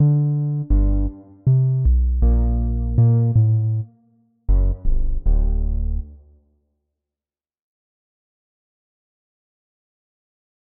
平稳上升的反复启动充能效果
描述：用Adobe Audition的音源生成的一个平稳上升的Shepard音色。通过7个不同的八度音产生了正弦波的音调，并将结果混合在一起。
标签： 正弦 升起 正弦波 反复
声道立体声